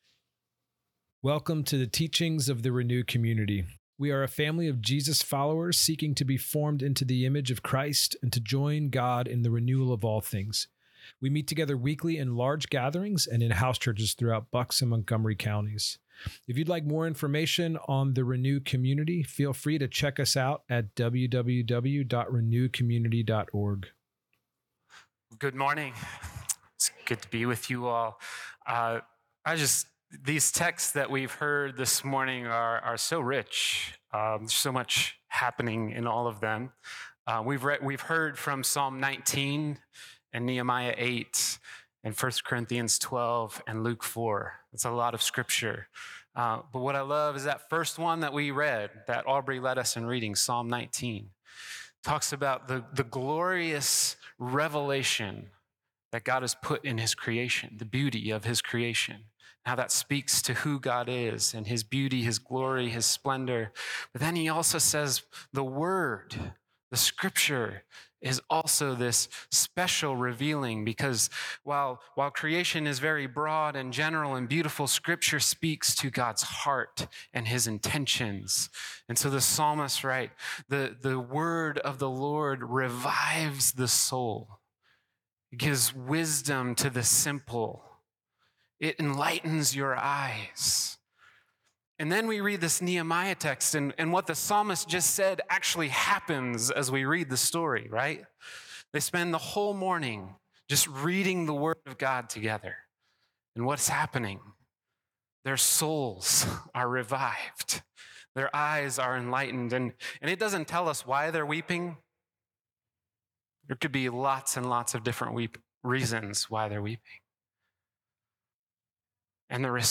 These are teachings from our gatherings and thoughts and stories from The InBetween.